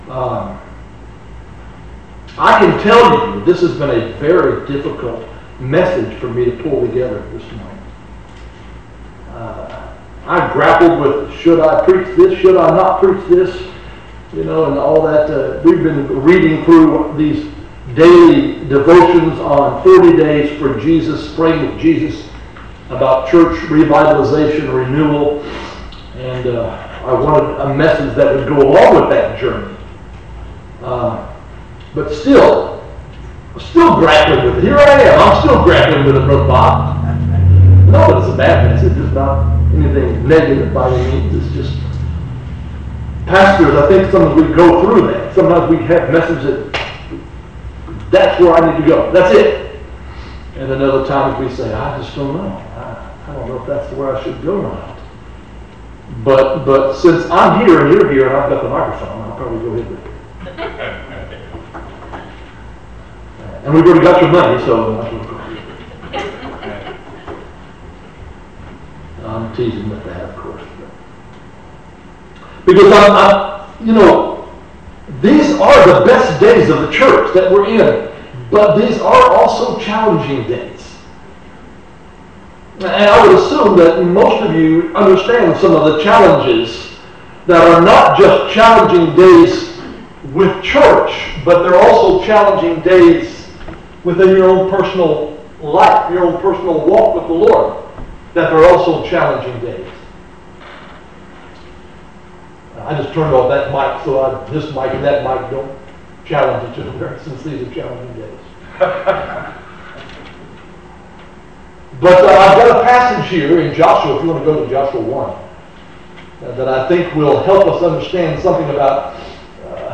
Sermons - Jackson Ridge Baptist Church